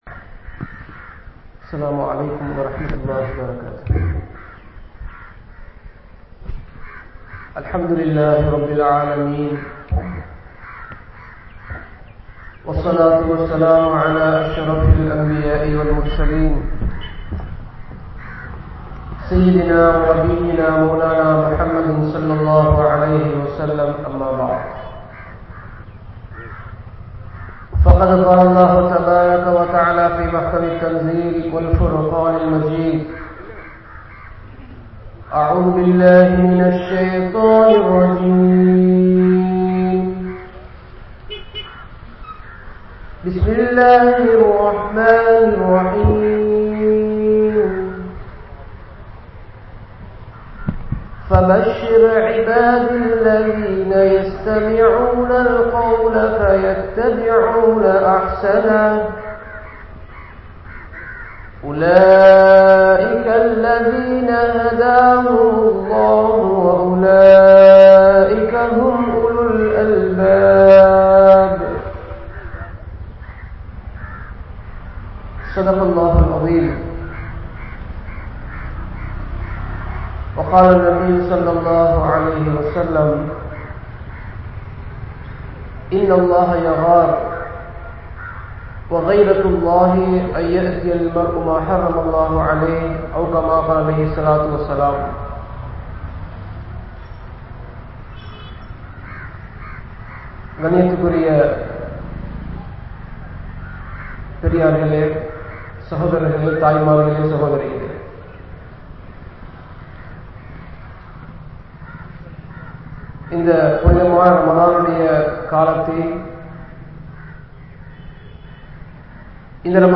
Allah`vai Payanthu Vaalungal (அல்லாஹ்வை பயந்து வாழுங்கள்) | Audio Bayans | All Ceylon Muslim Youth Community | Addalaichenai